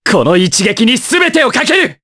Kasel-Vox_Skill4_jp.wav